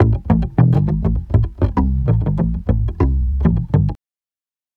Bass Lick 34-12.wav